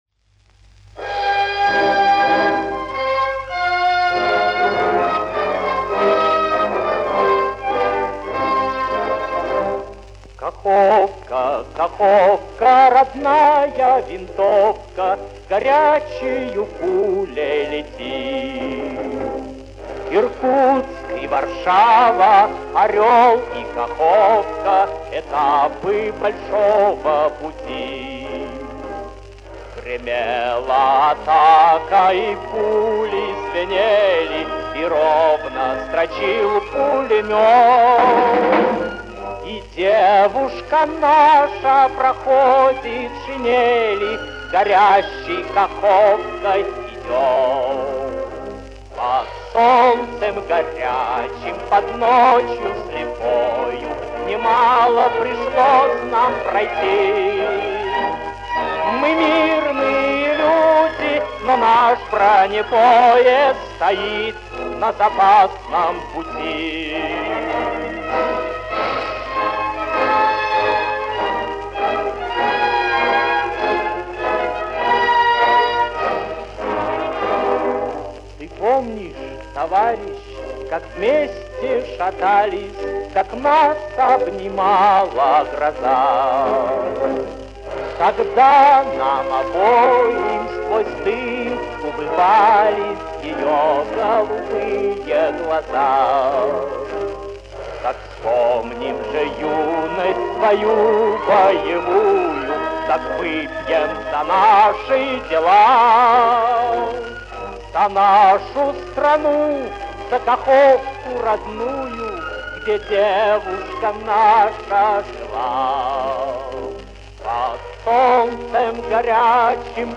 Музыка к кинофильмам